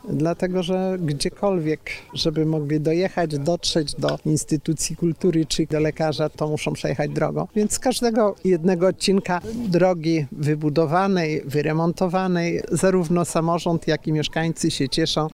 Wójt gminy Zbójna Elżbieta Parzych podkreśliła, że okolicznych mieszkańców remonty dróg są najważniejszymi inwestycjami.